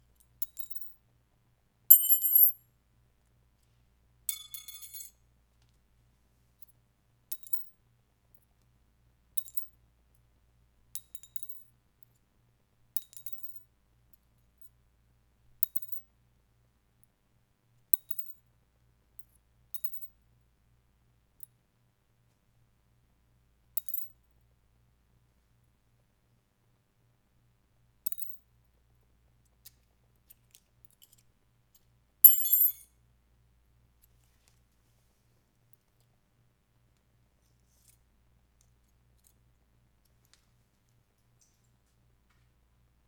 bullet-casings ding foley shell unedited sound effect free sound royalty free Voices